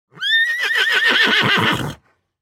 دانلود صدای اسب 14 از ساعد نیوز با لینک مستقیم و کیفیت بالا
جلوه های صوتی